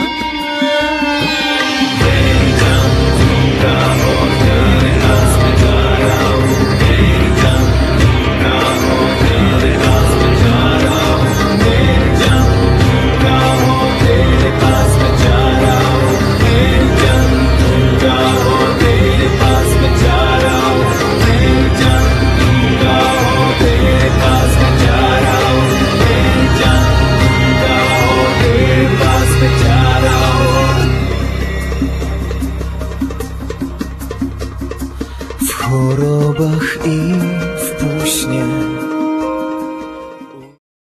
śpiew alikwotowy, sarangi